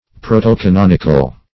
Search Result for " protocanonical" : The Collaborative International Dictionary of English v.0.48: Protocanonical \Pro`to*ca*non"ic*al\, a. Of or pertaining to the first canon, or that which contains the authorized collection of the books of Scripture; -- opposed to deutero-canonical .